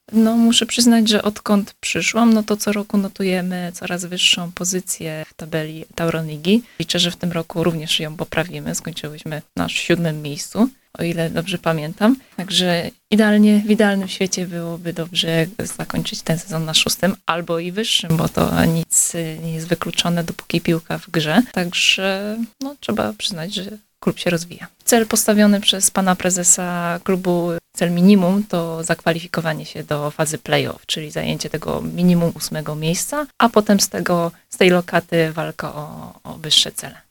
Cała rozmowa